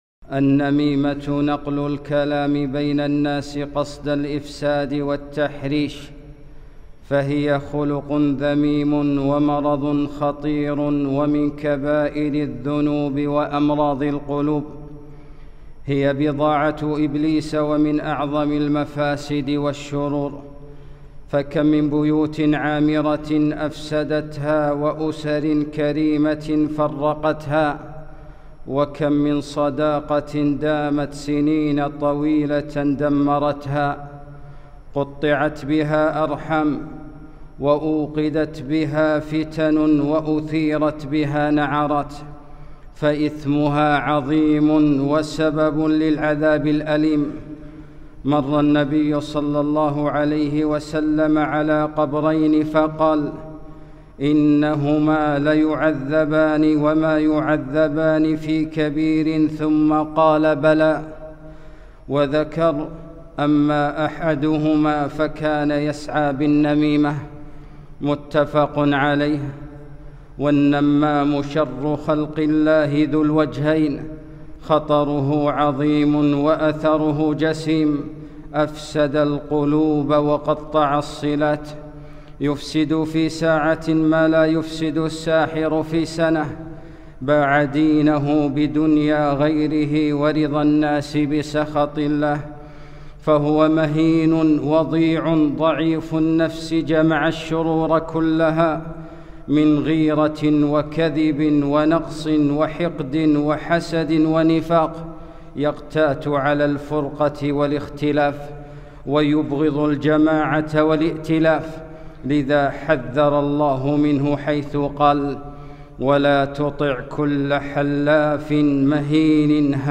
خطبة - النميمة